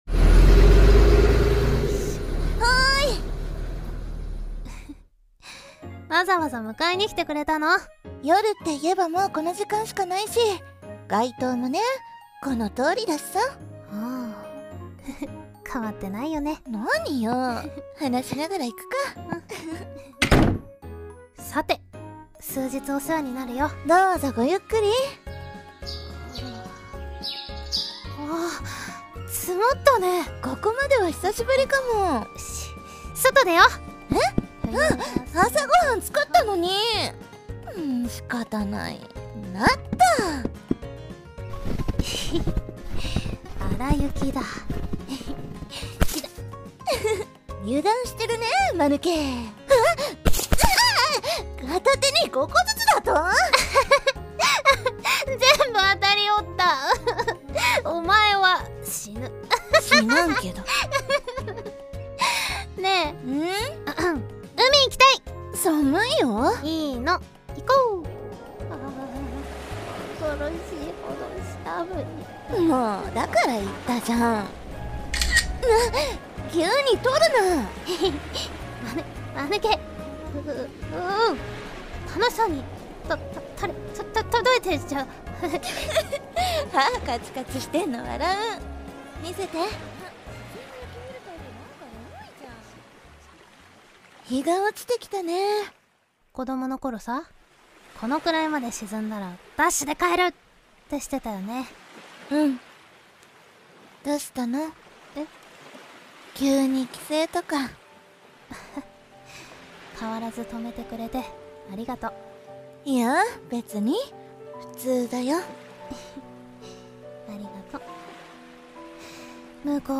【掛け合い 2人 声劇】